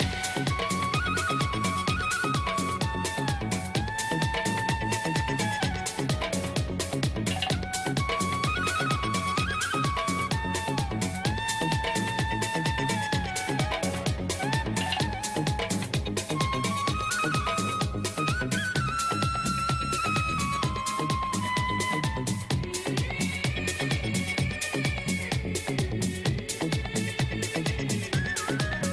Flute Ringtones